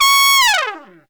Index of /90_sSampleCDs/Best Service ProSamples vol.25 - Pop & Funk Brass [AKAI] 1CD/Partition C/TRUMPET FX3